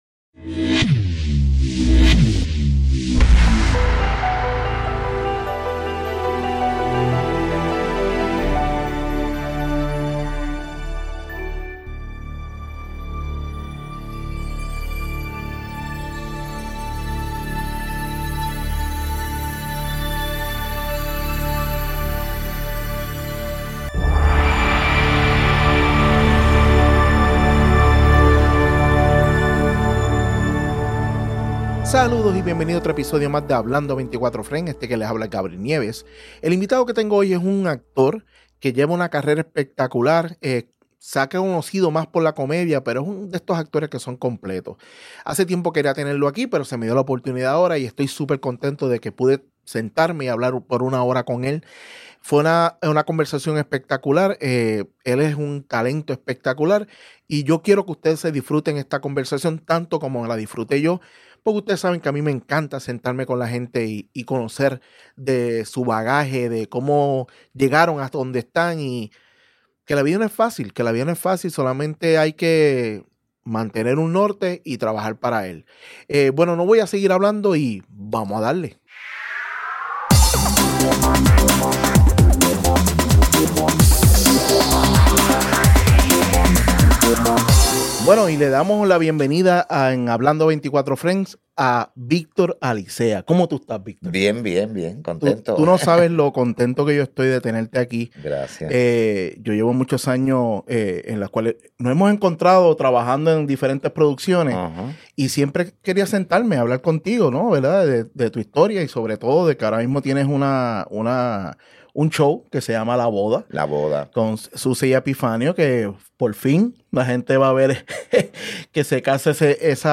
En este episodio tuve el privilegio de sentarme a dialogar con un gran actor de PR Victor Alicea. Tremenda conversación donde abarcamos su carrera y como es llevar 34 años de pareja comica con su personaje de Epifanio y Susa.